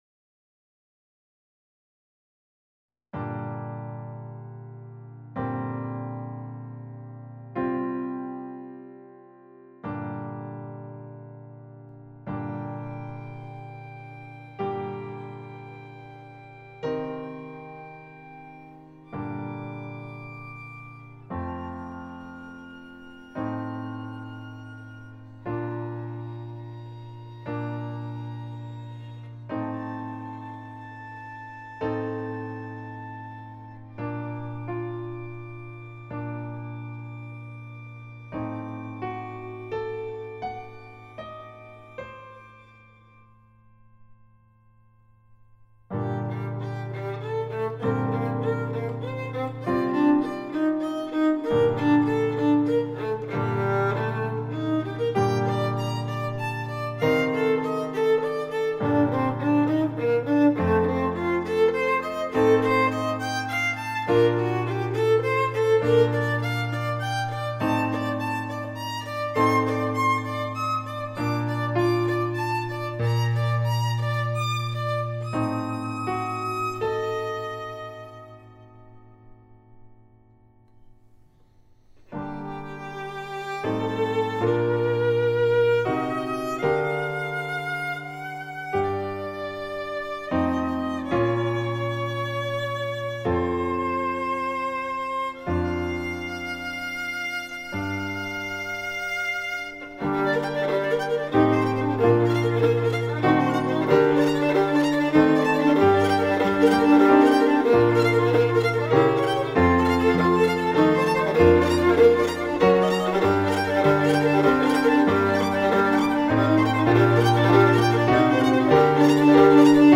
A videoinstalação corpoalma é, na sua concepção, pensada para música original. A montagem na Galeria CAL, em Brasília, foi concebida para piano e violino.
piano-e-violino.mp3